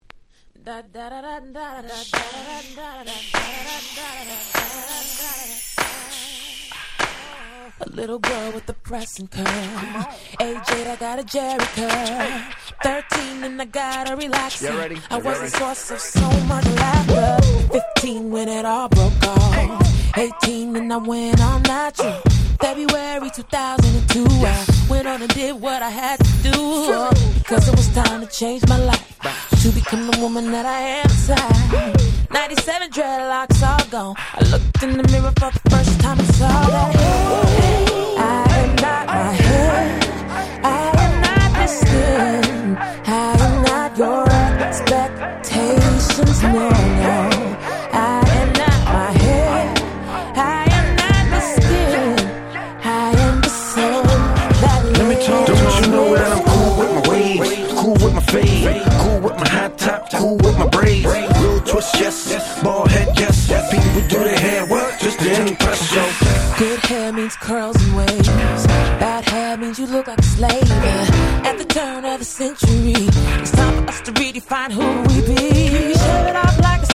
※試聴ファイルは別の盤から録音してございます。
06' Smash Hit R&B / Neo Soul !!
それぞれフロア向けのなかなか良いHip Hop Soulに仕上がっております。